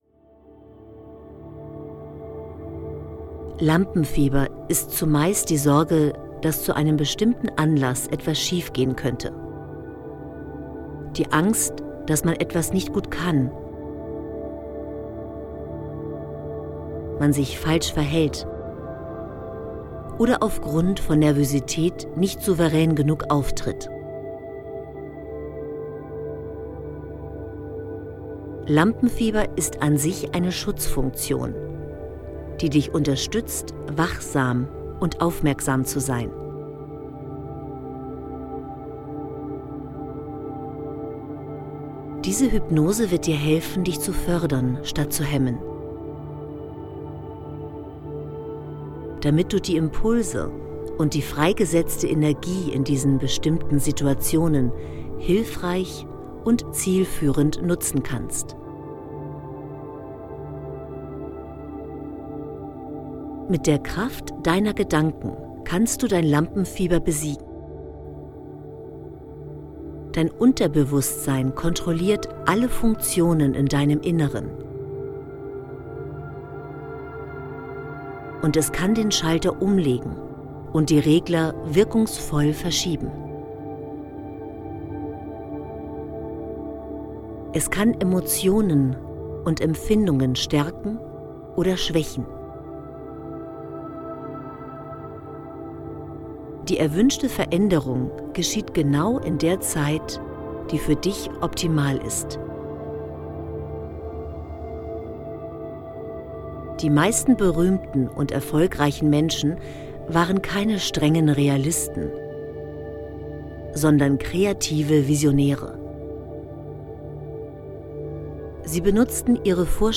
Sie können zwischen zwei Varianten wählen: ein Titel mit Brainwave Music und einer ohne diese spezielle neurologische Rhythmusmusik sogenannte Isochronic Beats.
Jede Anwendung ist ein harmonisches Zusammenspiel von inspirierenden Texten, bewegenden Stimmen und sanft stimulierender Begleitmusik.